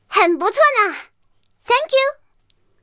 M5A1中国版强化语音.OGG